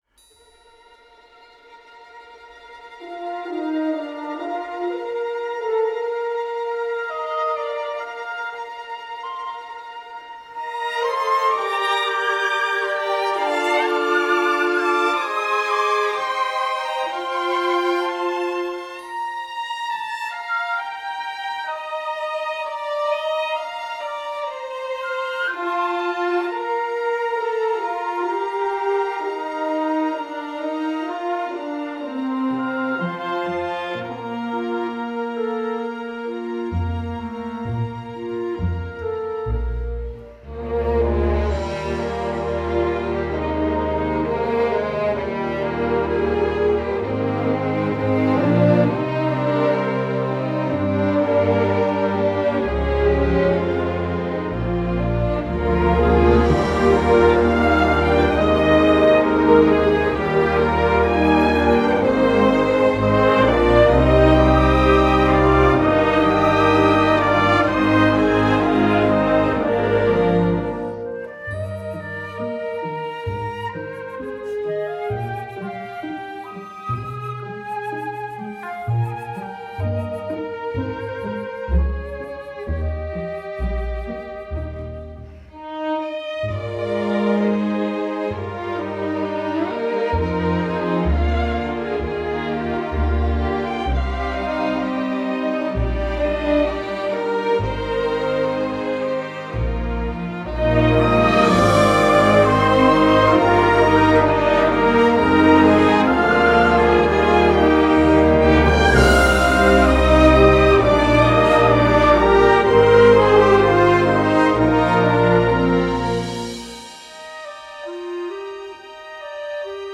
Voicing: 5tring Orchestra